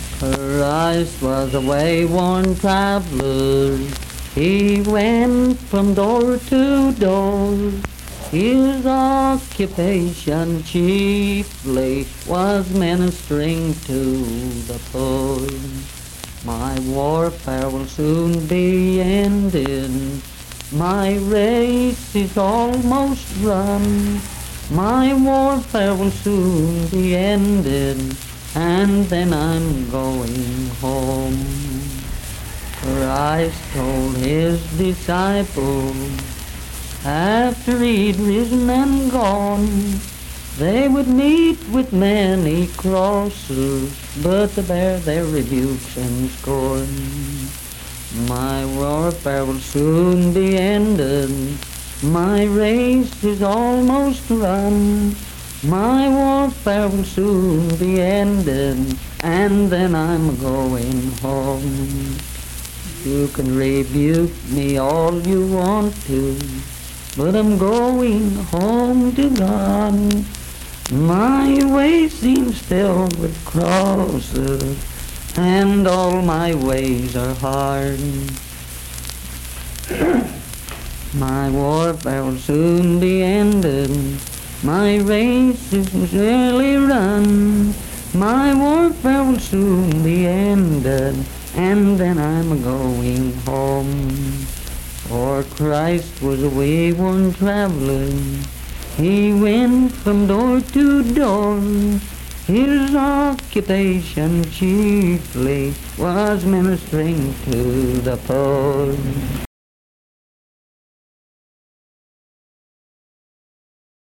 Unaccompanied vocal music performance
Hymns and Spiritual Music
Voice (sung)
Wirt County (W. Va.)